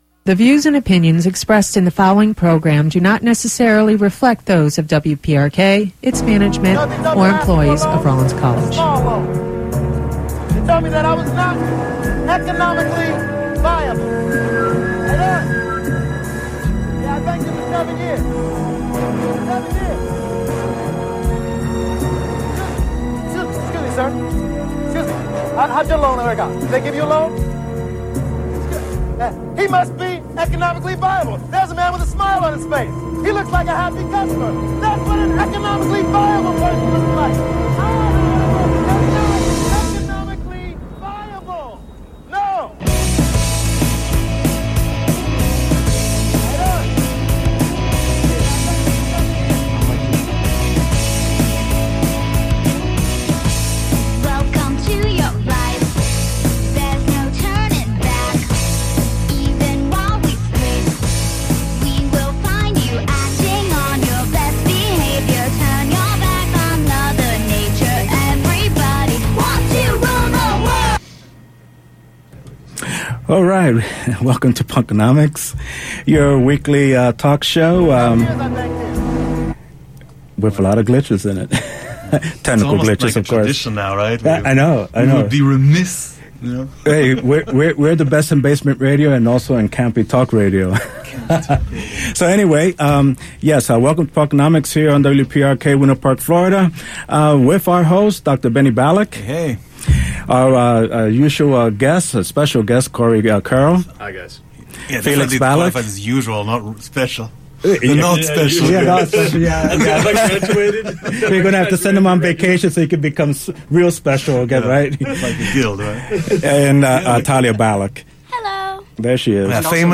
EDIT: The end of the show was lost due to a glitch caused by a thunderstorm :(